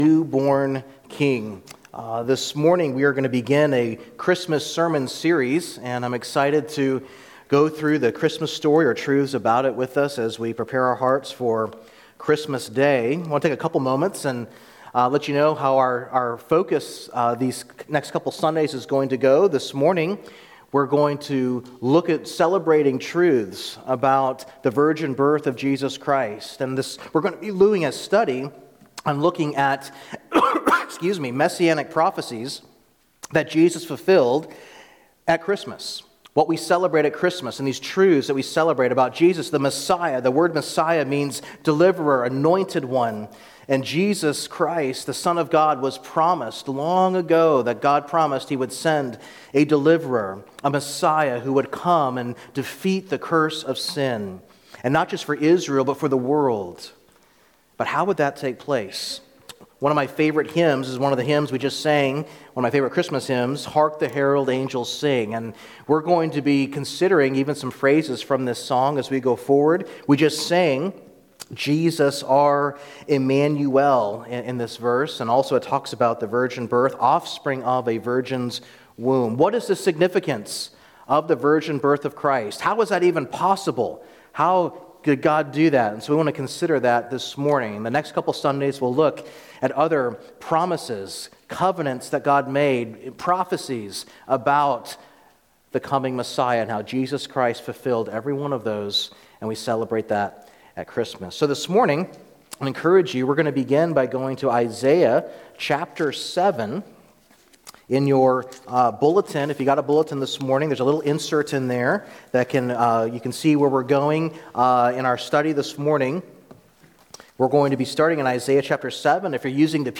A message from the series "Christmas 2025." God calls us to believe in Jesus Christ and rejoice in His glorious gift of salvation through His Son.